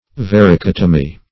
Search Result for " varicotomy" : The Collaborative International Dictionary of English v.0.48: Varicotomy \Var`i*cot"o*my\, n. [See Varix ; -tomy .]